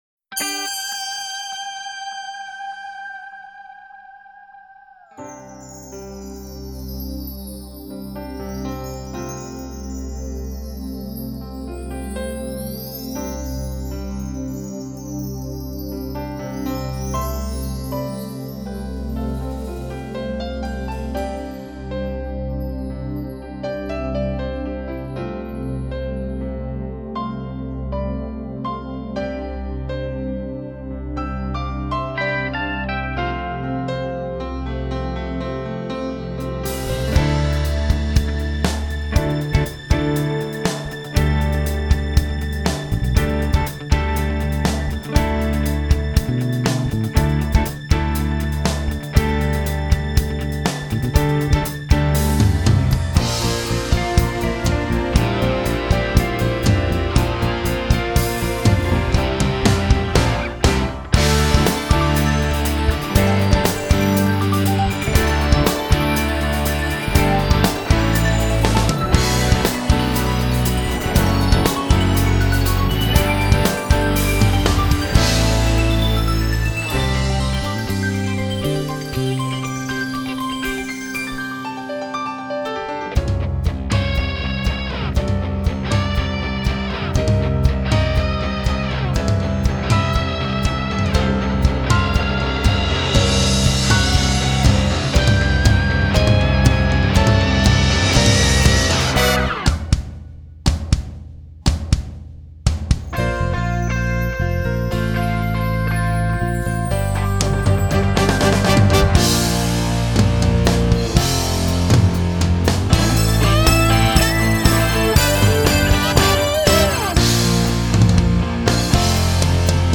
Performance Tracks